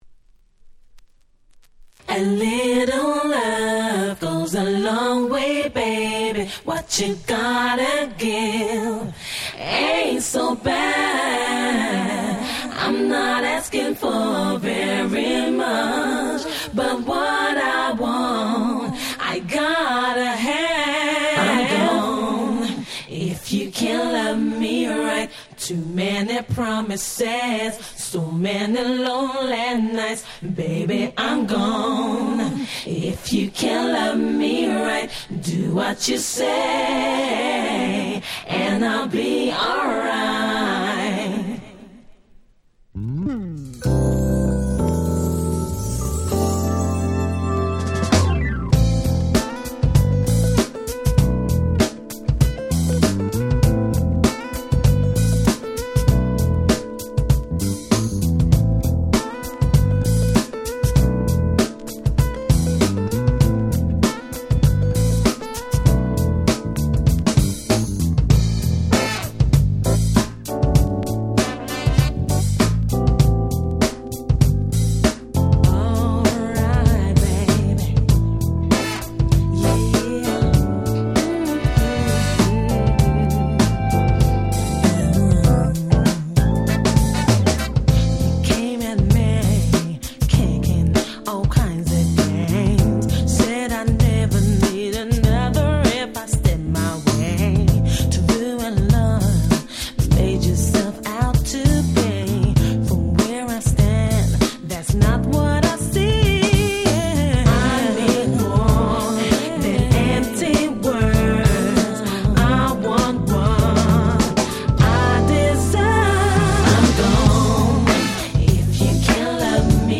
97' Very Nice UK R&B !!
詳細が一切不明の女性R&B。
90's R&B